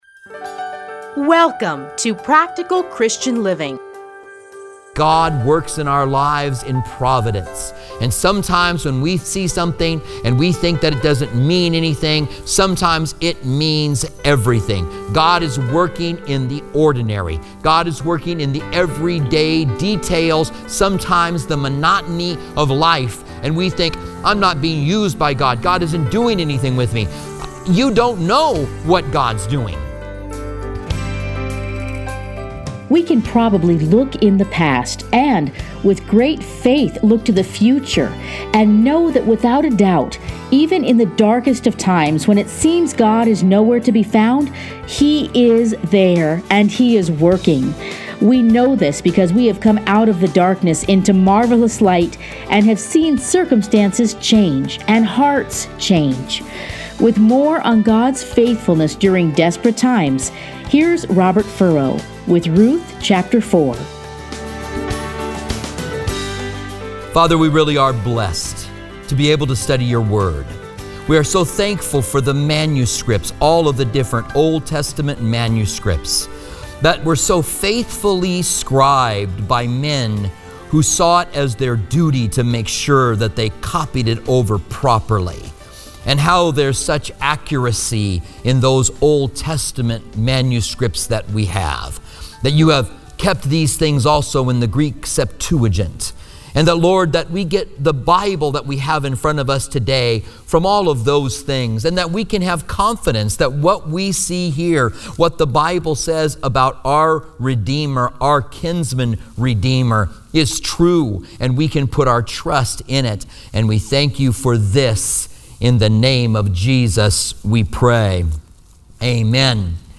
edited into 30-minute radio programs titled Practical Christian Living. Listen here to a teaching from Ruth.